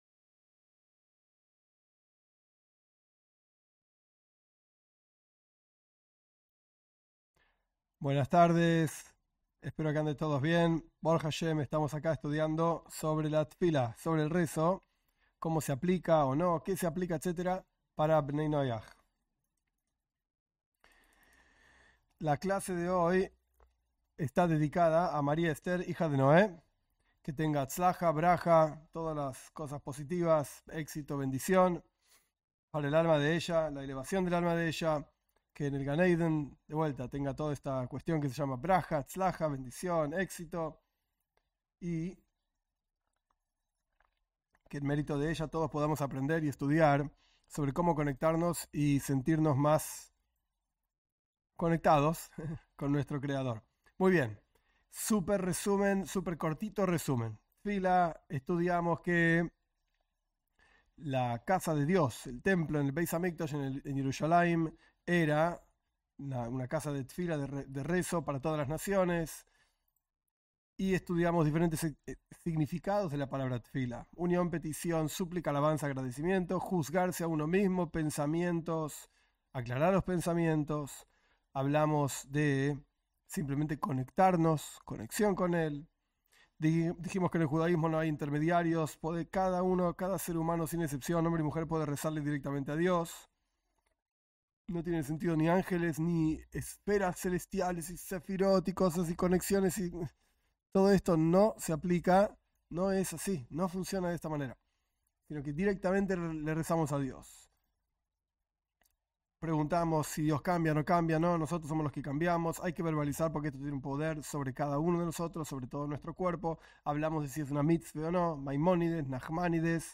En estas clases se analiza con detenimiento la plegaria para no Judíos según el mensaje del judaísmo.